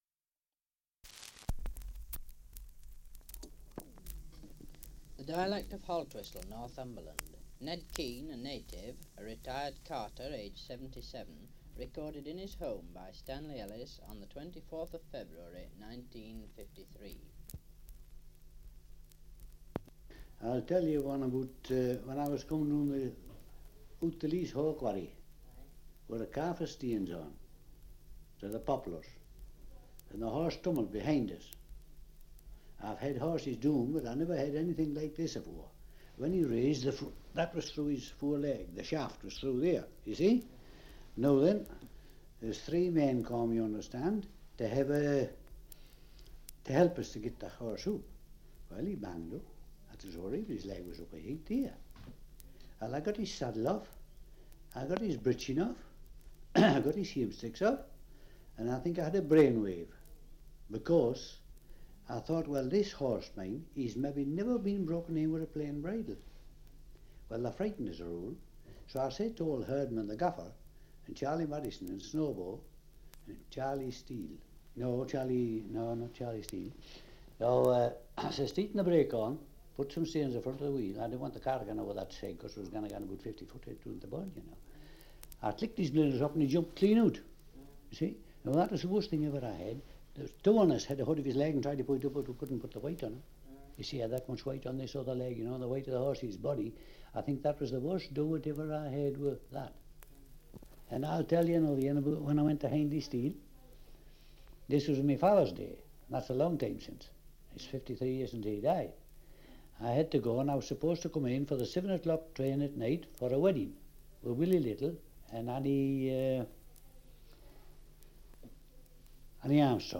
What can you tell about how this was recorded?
Survey of English Dialects recording in Haltwhistle, Northumberland 78 r.p.m., cellulose nitrate on aluminium